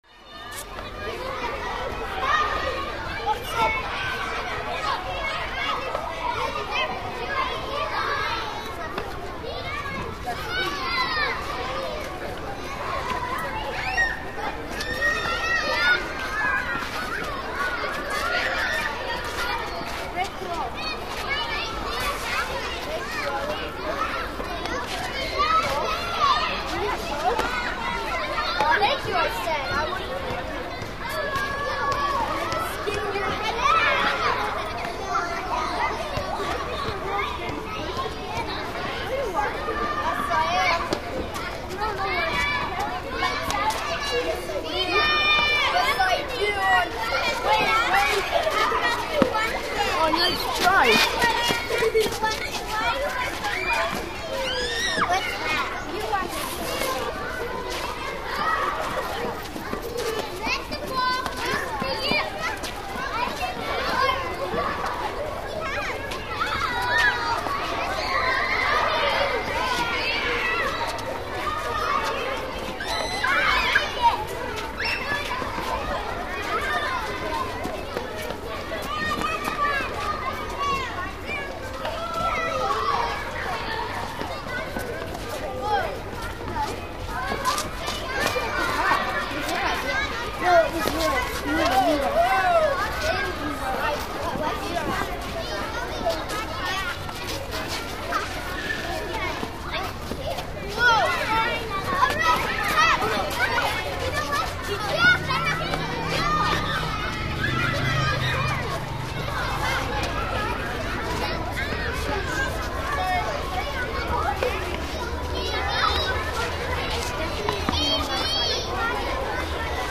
Дети веселятся на улице